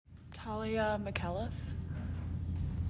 Pronunciation: TAL ya mih CAL if